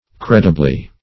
Credibly \Cred"i*bly\, adv.